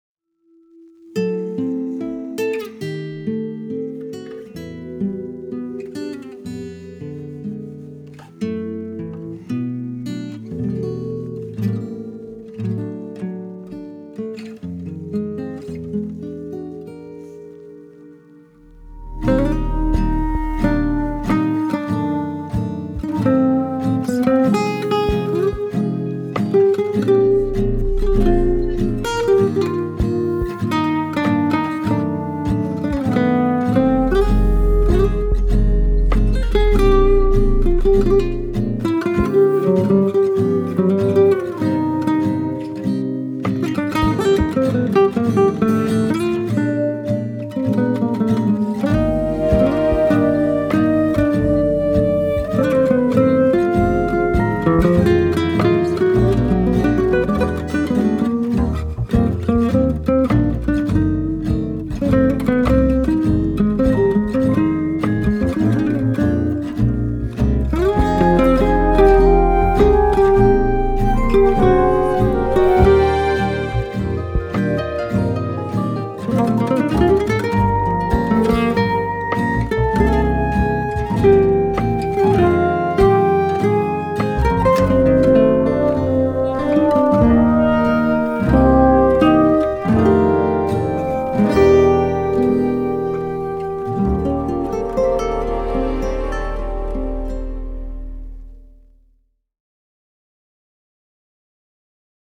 Compositeur de la Bande Son